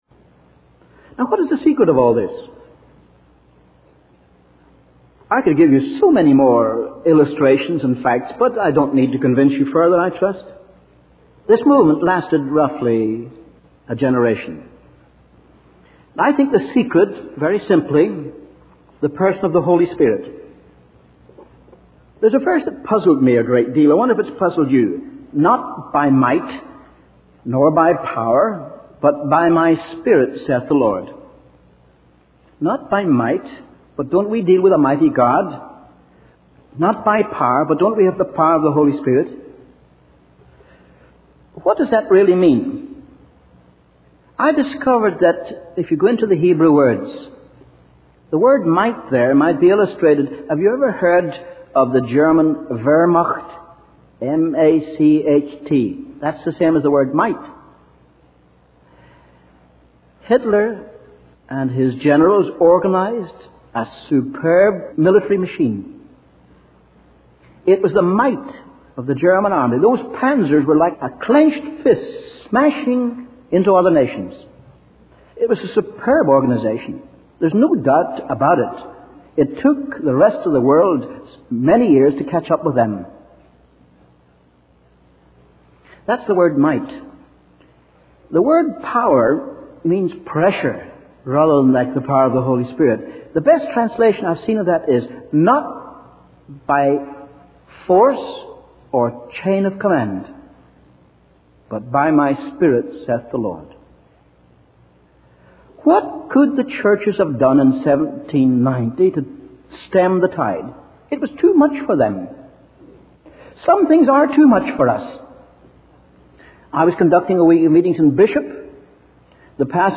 In this sermon, the preacher uses various illustrations to convey his message.